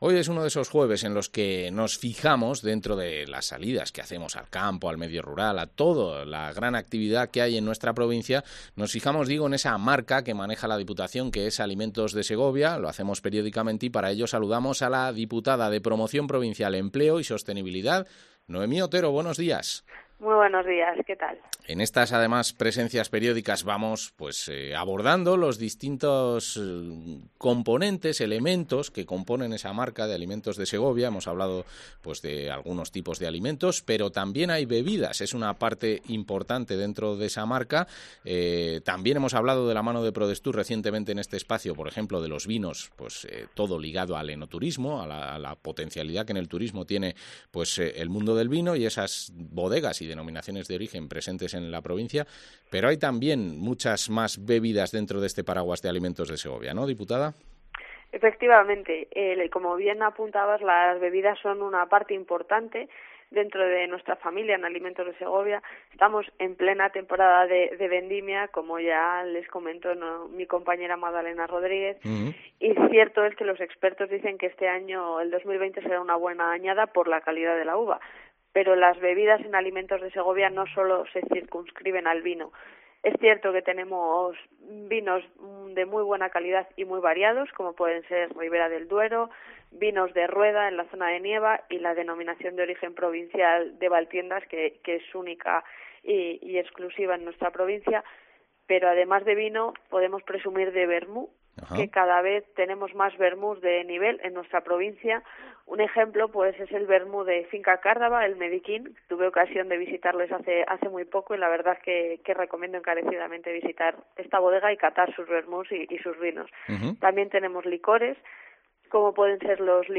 La diputada Noemí Otero habla de las cervezas artesanas de la marca Alimentos de Segovia